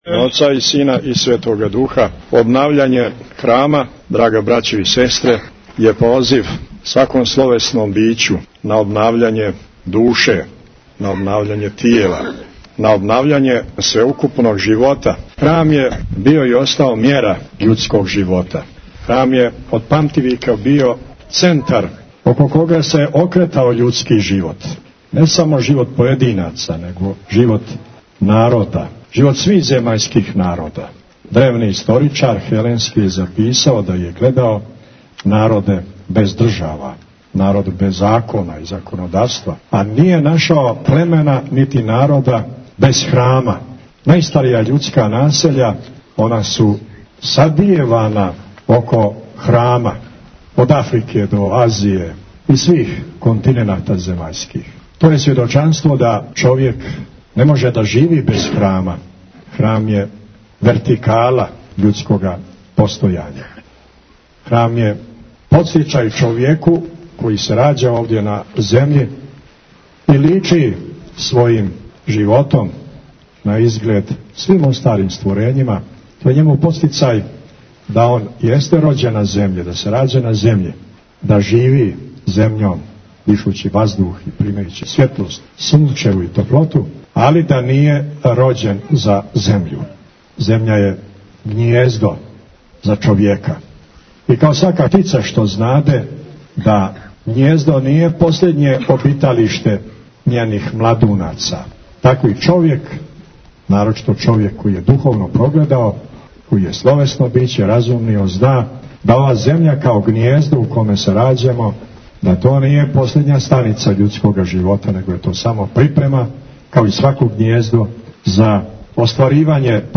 Бесједа Митрополита Амфилохија са Свете Архијерејске Литургије, 11. новембра, црква Свете Недјеље у селу Побори код Будве
Бесједа Његовог Високопреосвештенства Архиепископа Цетињског Митрополита Црногорско-приморског Г. Амфилохија са Свете Архијерејске Литургије коју је са свештенством служио у цркви Свете Недјеље у селу Побори код Будве.